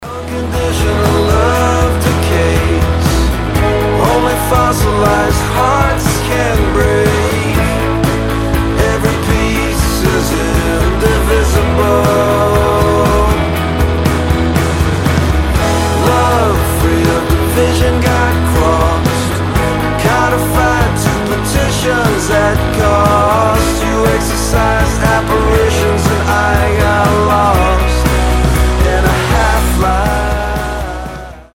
• Качество: 320, Stereo
мужской вокал
Alternative Rock
приятный мужской голос